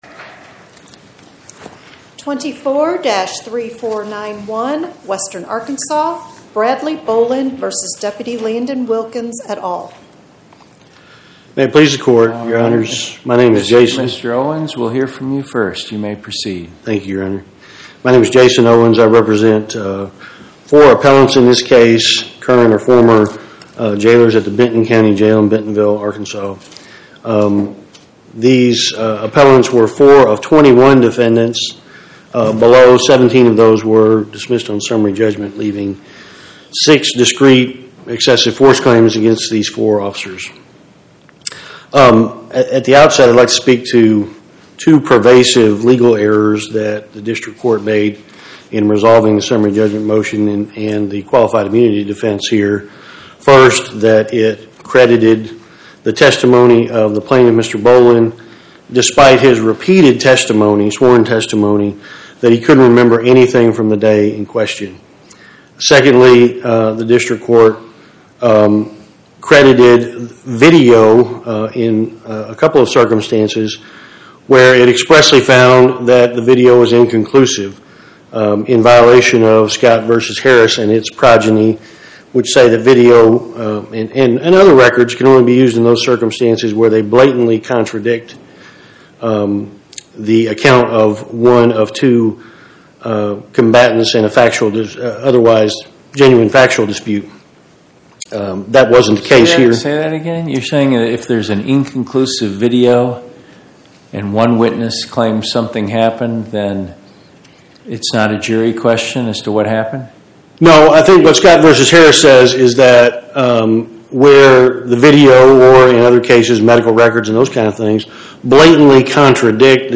Oral argument argued before the Eighth Circuit U.S. Court of Appeals on or about 11/19/2025